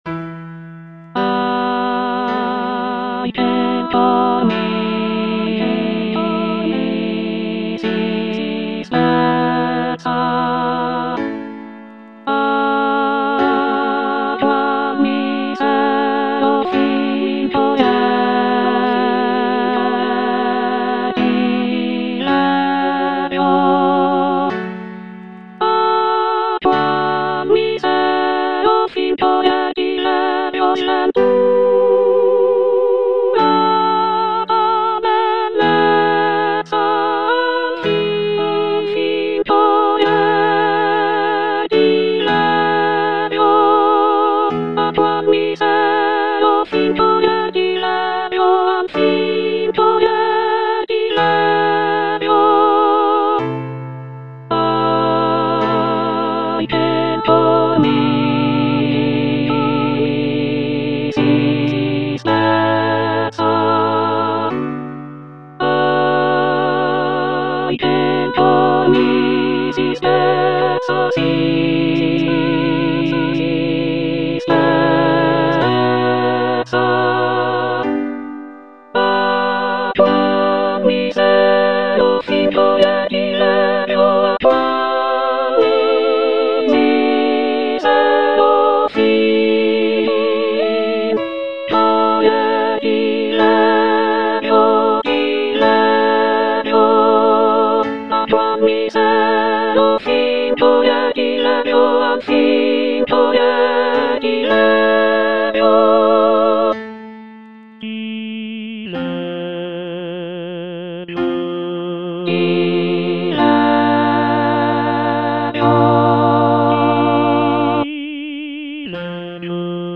soprano II) (Emphasised voice and other voices
solo vocal work